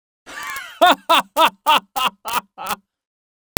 Laugh Male
Laugh Male.wav